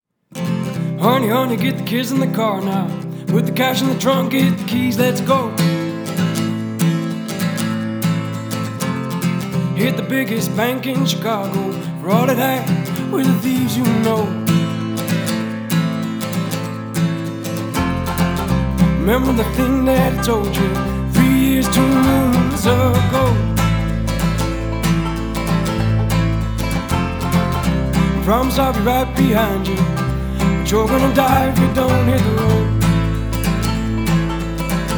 Contemporary Folk